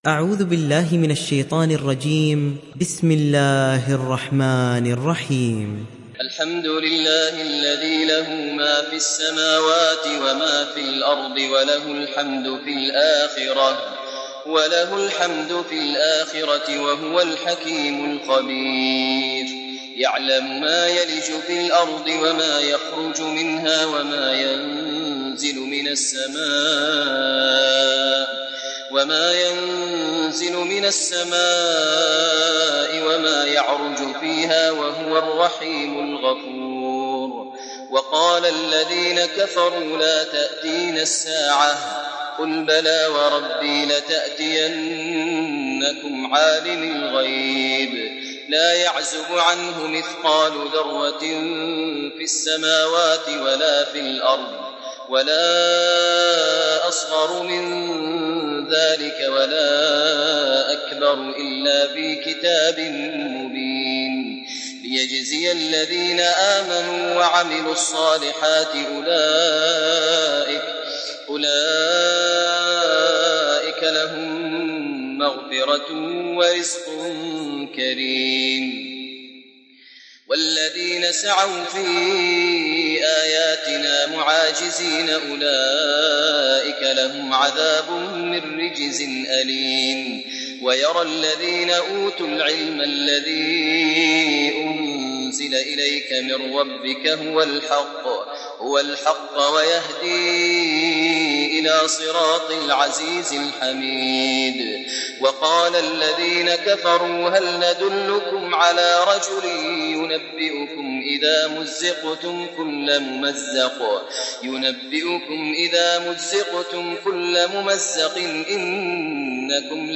دانلود سوره سبأ mp3 ماهر المعيقلي روایت حفص از عاصم, قرآن را دانلود کنید و گوش کن mp3 ، لینک مستقیم کامل